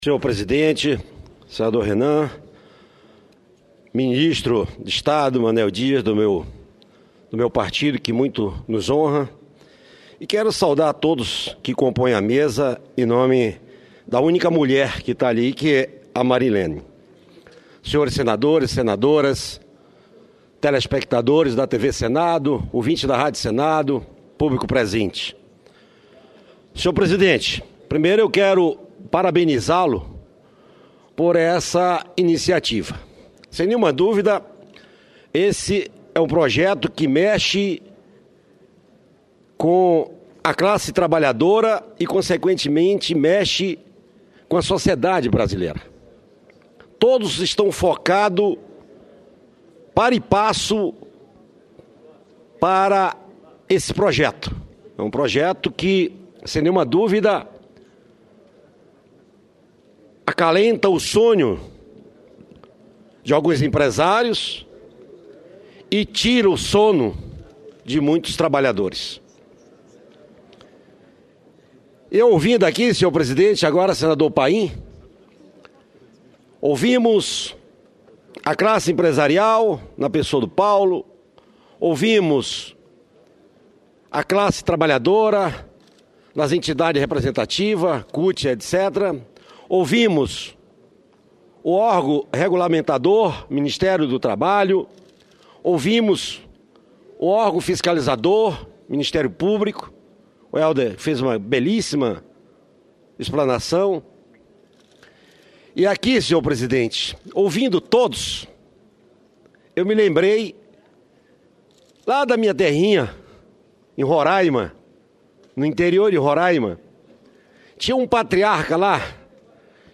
Pronunciamento do senador Telmário Mota